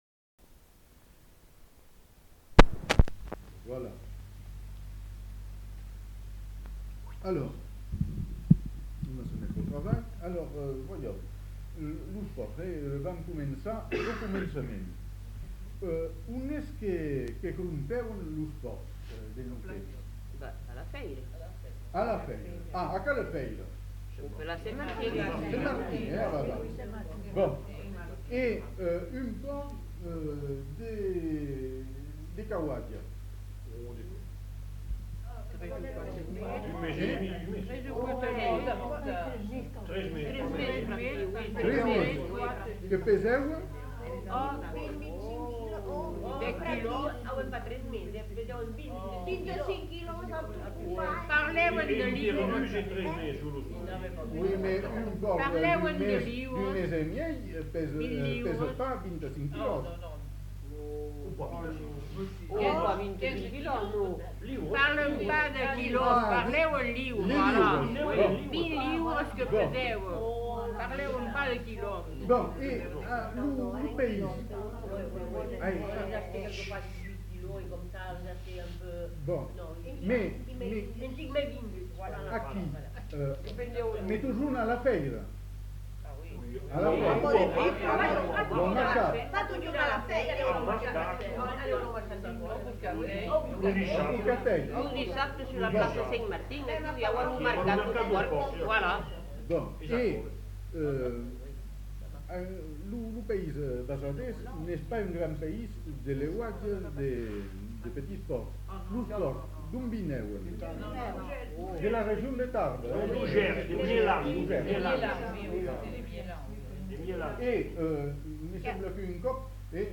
Lieu : Bazas
Genre : témoignage thématique
Les informateurs ne sont pas identifiés.